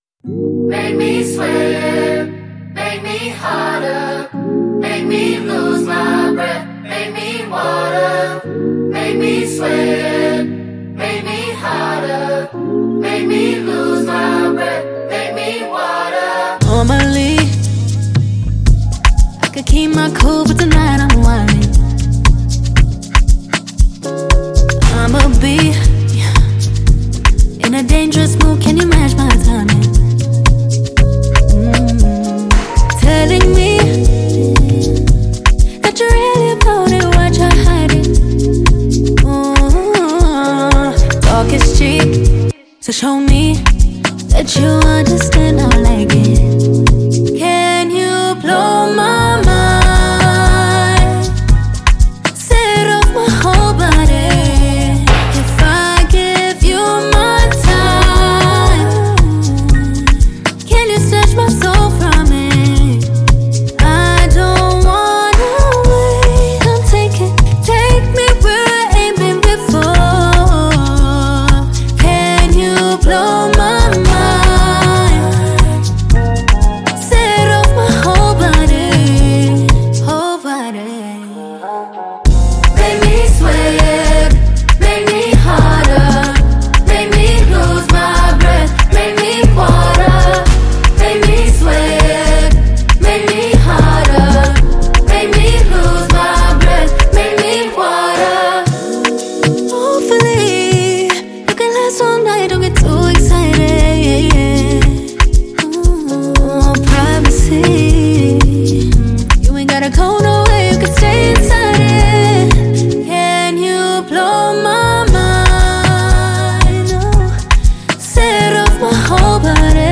Foreign MusicSouth African